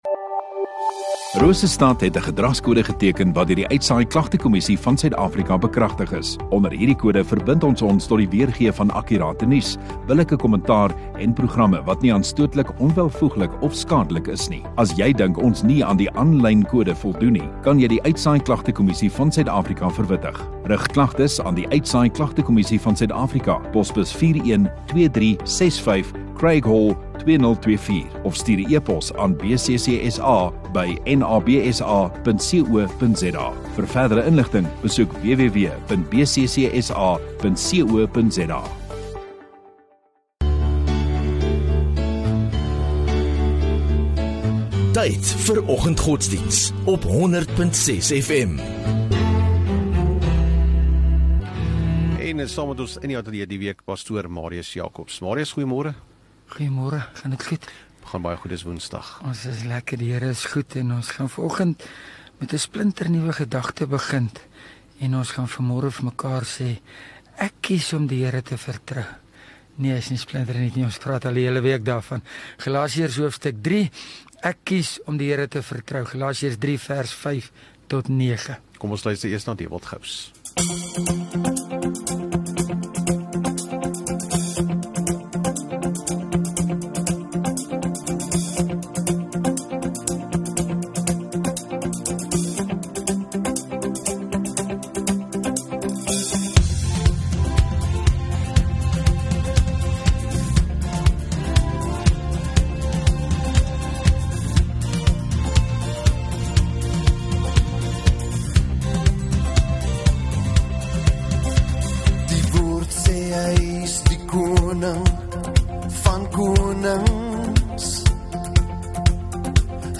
30 Oct Woensdag Oggenddiens